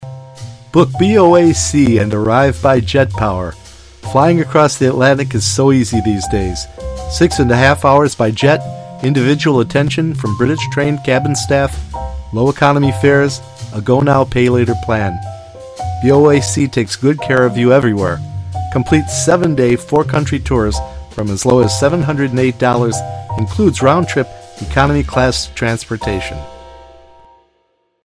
• Commercials
With a tone that’s clear, friendly and easy to listen to, I help brands share their stories, reach their audiences, and create personal experiences.
BOAC-Airlines_with-Music.mp3